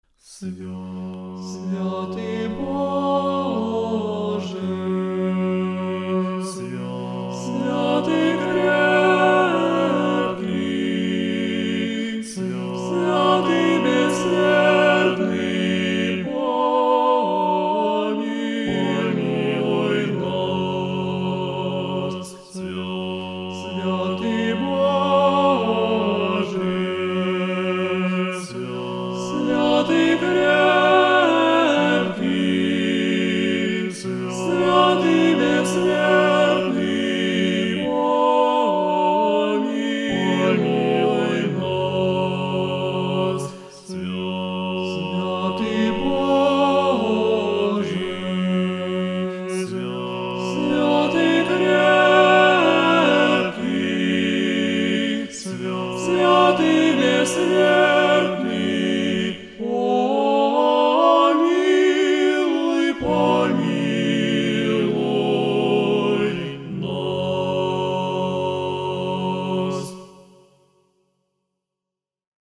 14 декабря 2025 года, в Неделю 27-ю по Пятидесятнице, архиепископ Новогрудский и Слонимский Гурий совершил Божественную Литургию в Свято-Успенском соборе Жировичского монастыря.
Богослужебные песнопения исполнил мужской хор Минской духовной семинарии
Архиерейский_хор_Минской_духовной_семинарии_Трисвятое_Пюхтицкое.mp3